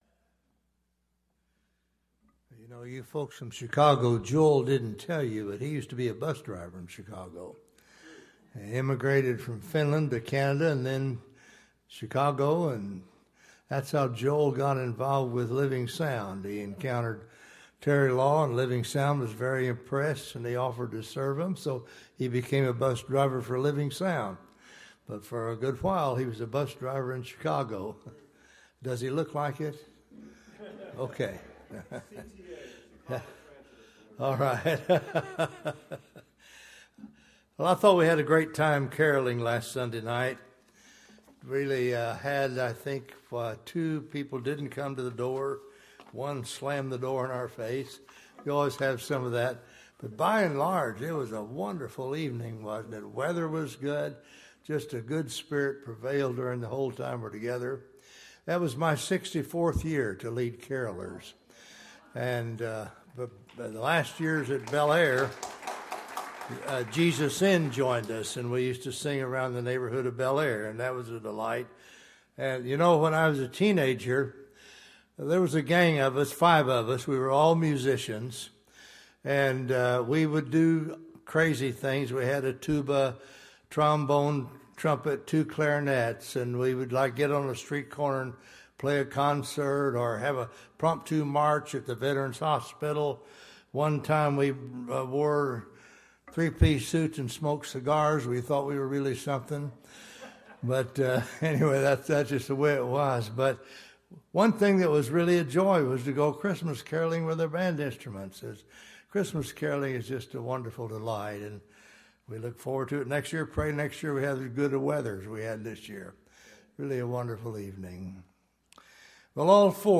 1 A Reckless Obsession - Sermon 1